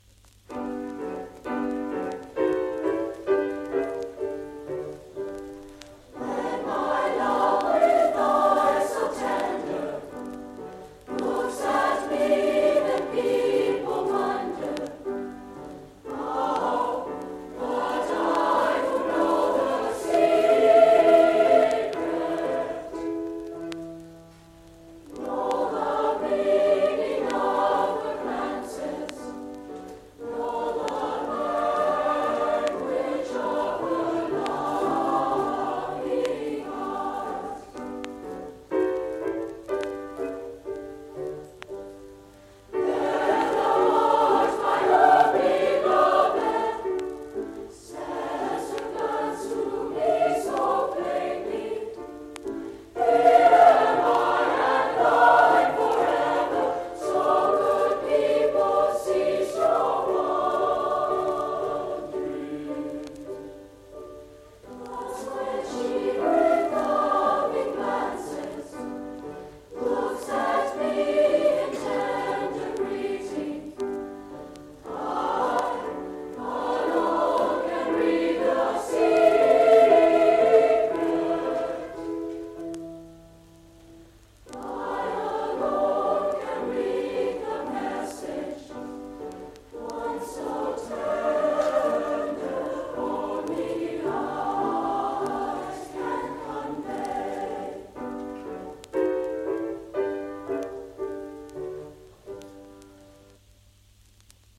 Youth Choir
Geheimnis, by Schubert (sung in English)